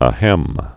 (ə-hĕm)